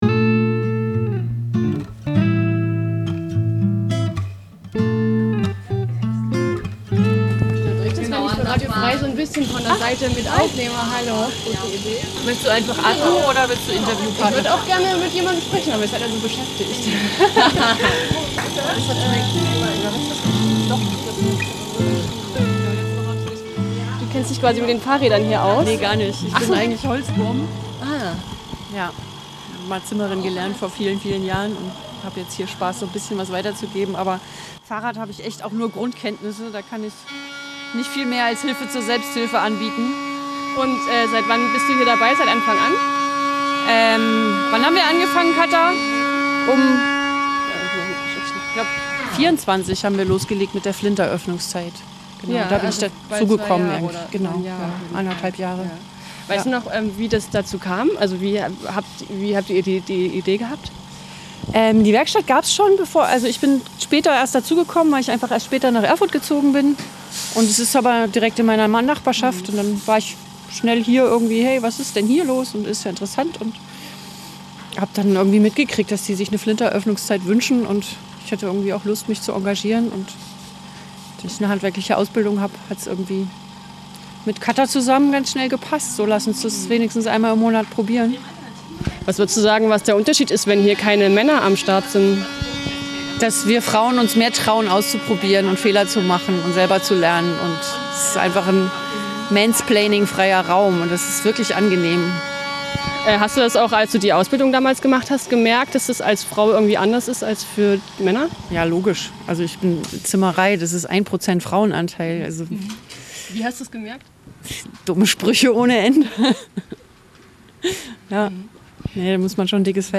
Was ist anders, wenn ohne Männer gebastelt, geschraubt und repariert wird? Einiges - sagen die, die Mitte März bei der Frauen*Werkstattzeit bei Build-Share-Repair in der Magdeburger Alle waren.